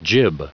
Prononciation du mot jib en anglais (fichier audio)